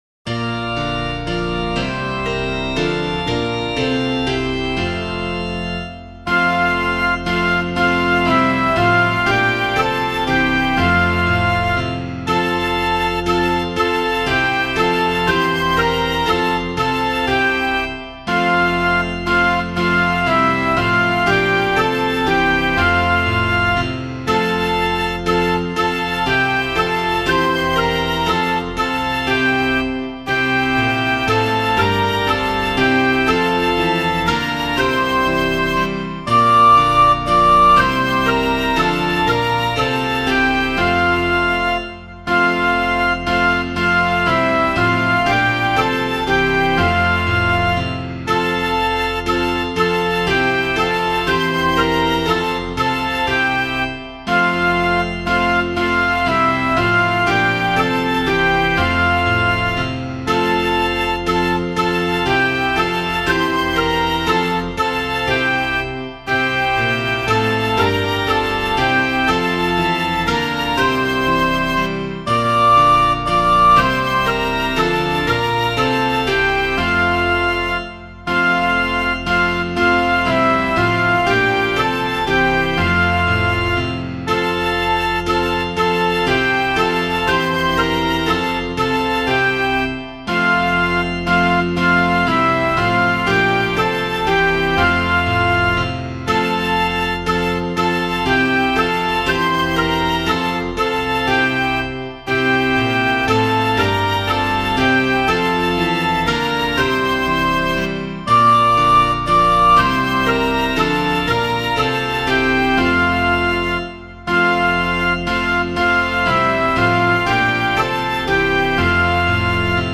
Hymn